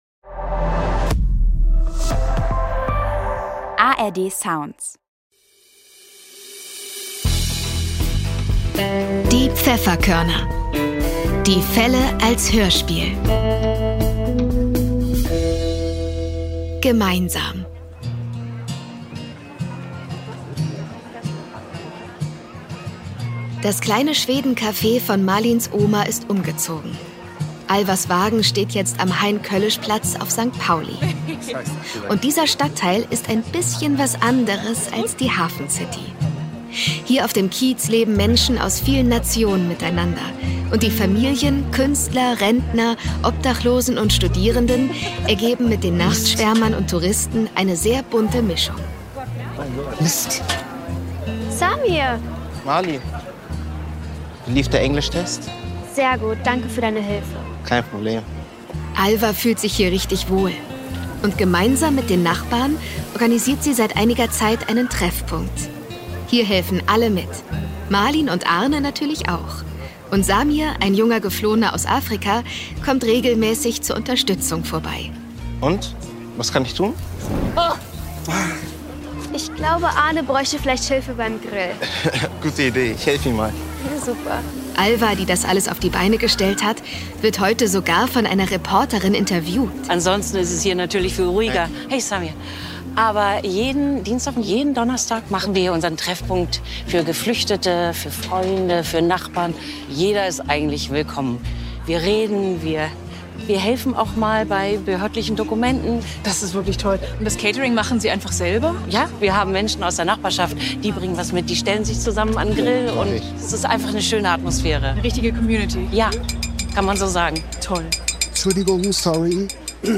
Gemeinsam (15/21) ~ Die Pfefferkörner - Die Fälle als Hörspiel Podcast